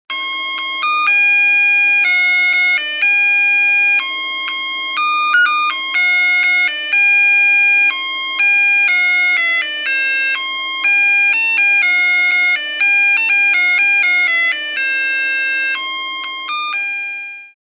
迫力と美しさに声も出ません   開けると
音楽が鳴る♪毛沢東のライター
lighter.mp3